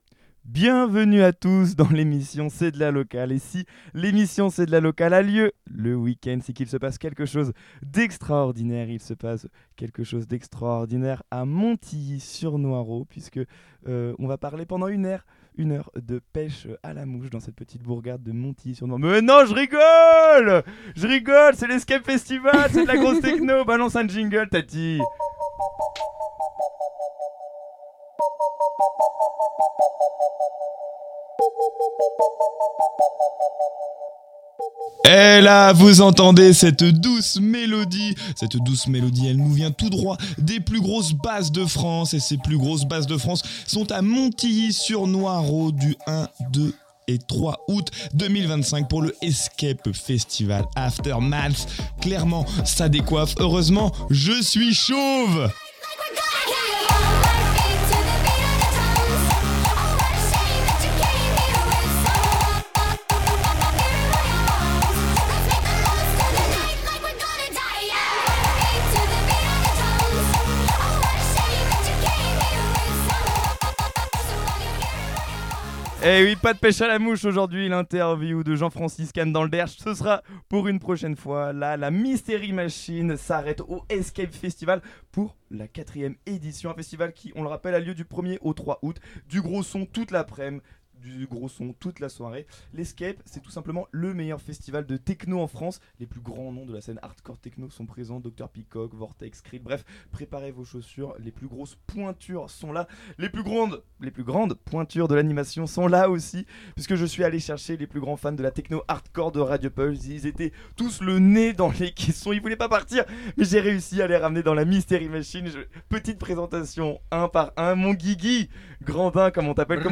La Mystery Machine de Rdiopulse n'a pas froid aux oreilles et s'est installée en plein cœur du festival Eskape, pour l'édition 2025.
Aussi, de nombreux invités sont venus présenter le festival et nous parler de leur rôle ainsi que de l'organisation de celui-ci. Vous l'aurez compris, dans cette émission respire, retrouvez des rires, des rencontres, des anecdotes et, évidemment, de la musique techno...
Petit avertissement : préparez vos oreilles, les BPM montent aussi vite que l'alcool après une tournée de shots. culture local tekno musique loisir eskape